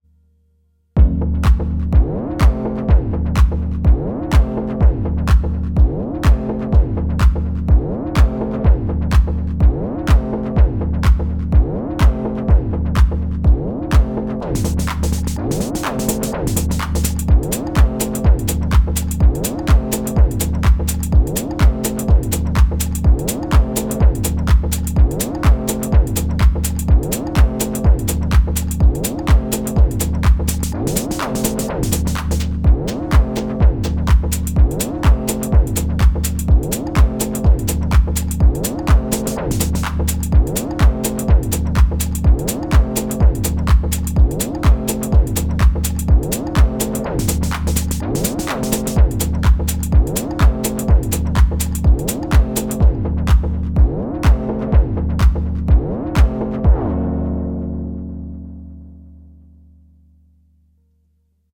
Stumbled on a cool little groove while FM-ing the chord machine and using portamento.